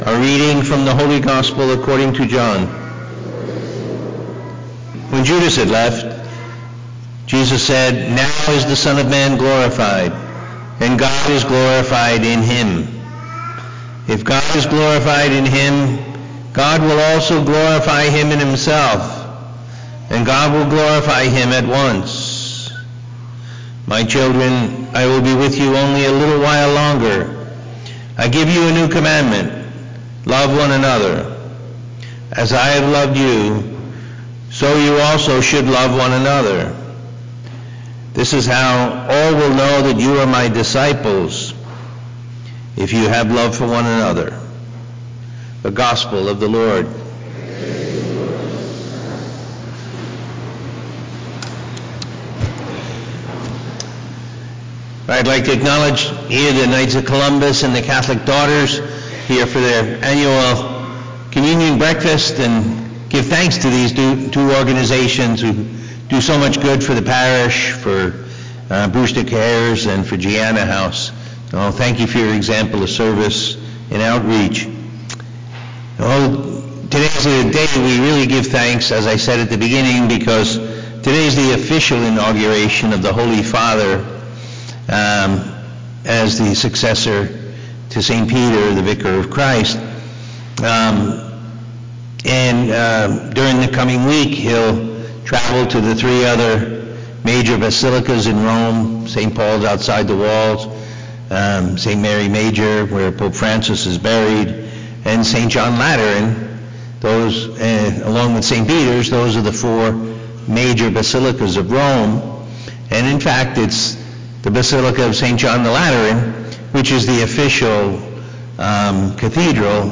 Looking to go deeper in your prayer life? Listen to the homily from the Sunday Mass and meditate on the Word of God.